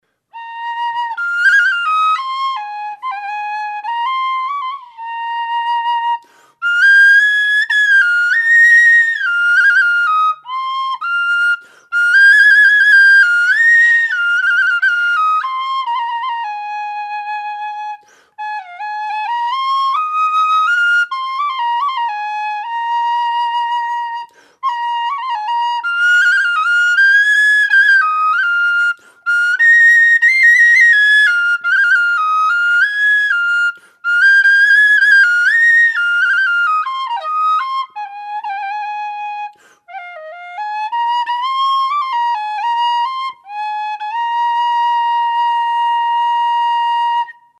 Eb whistle - 100 GBP
made out of thin-walled aluminium tubing with 12mm bore
Eb-impro2.mp3